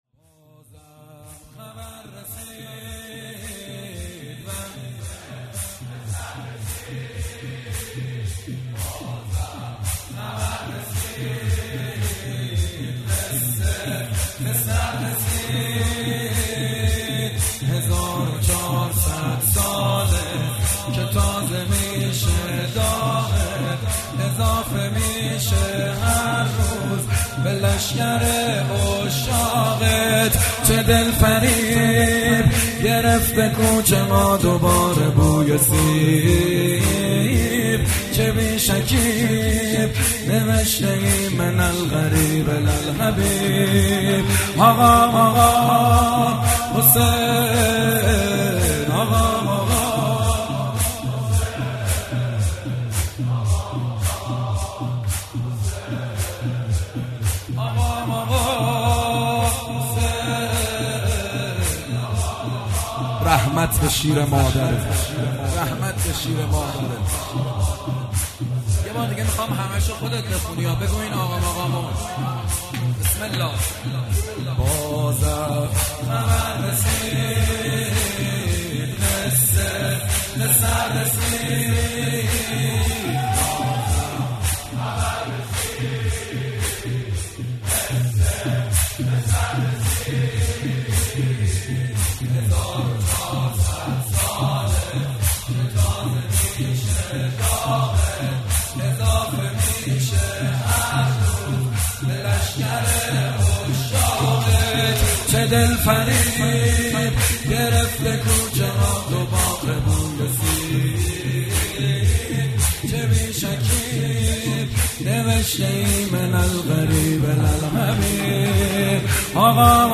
زمینه اول